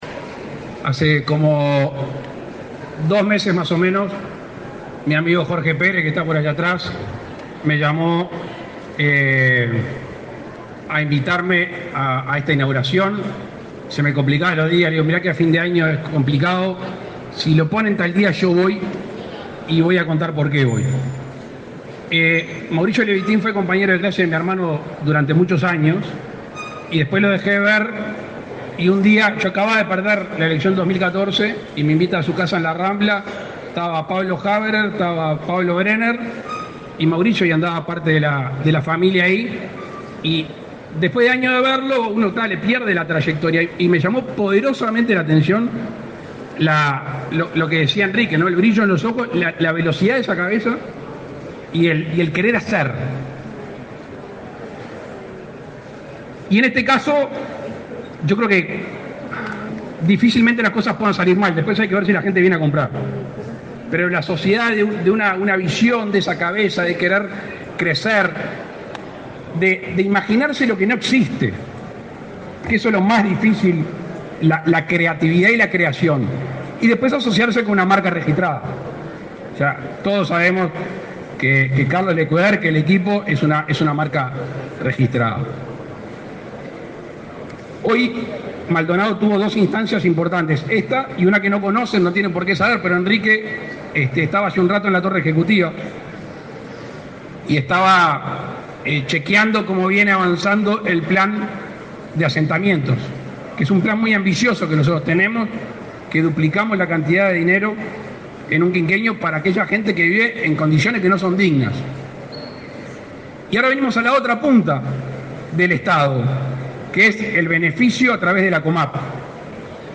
Palabras del presidente de la República, Luis Lacalle Pou
Con la presencia del presidente de la República, Luis Lacalle Pou, se realizó en Maldonado, este 15 de diciembre, la inauguración de Atlántico